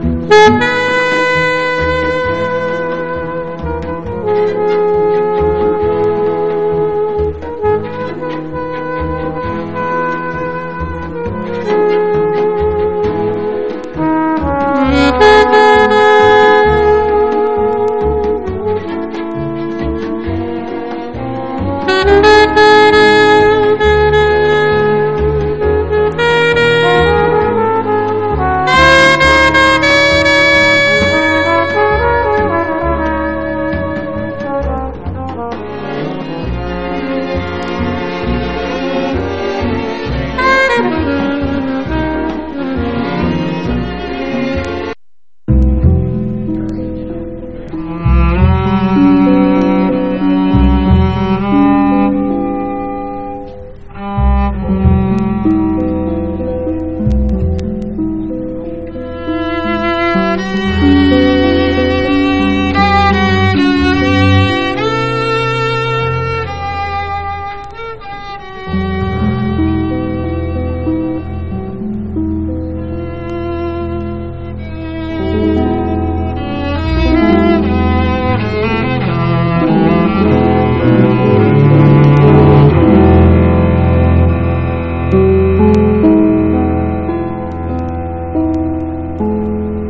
ユーロ・ジャズ的なコンテンポラリー・サウンドを聴かせます。